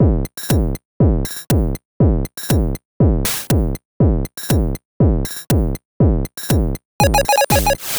120_BPM
ChipShop_120_Drums_06.wav